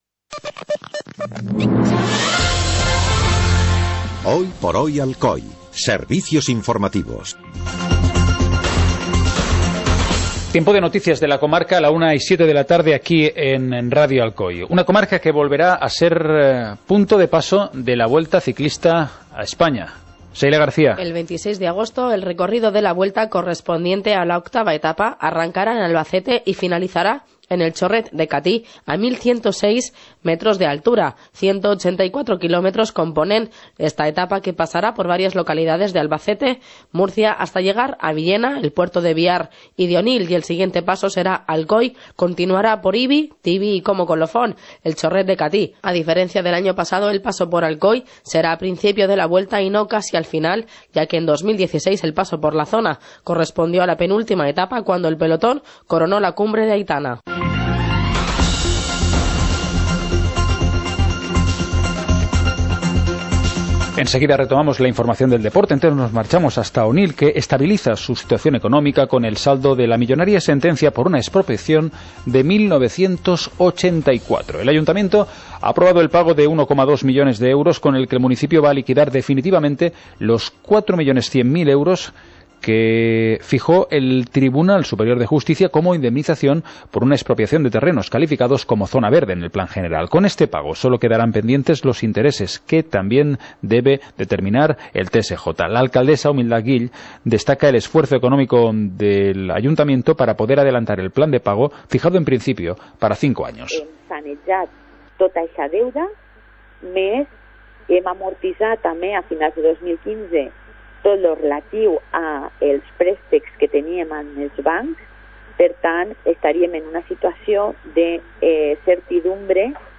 Informativo comarcal - viernes, 13 de enero de 2017